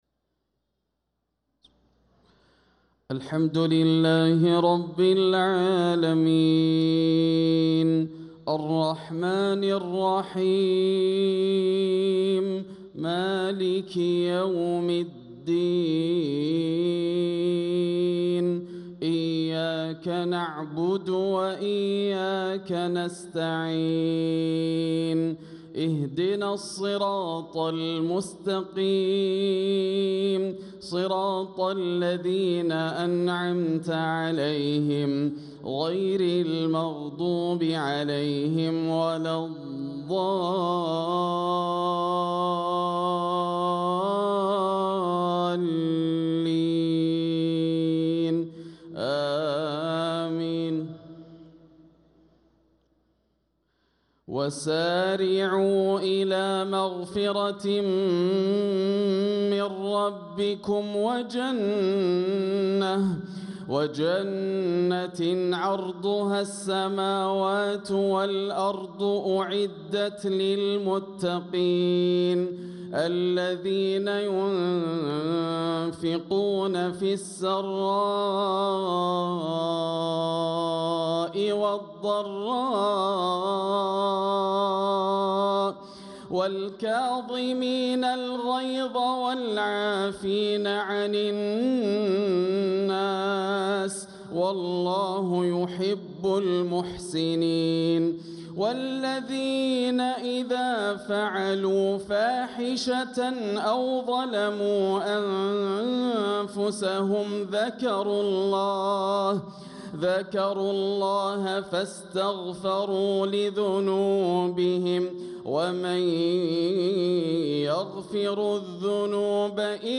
صلاة المغرب للقارئ ياسر الدوسري 18 رجب 1446 هـ